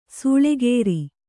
♪ sūḷegēri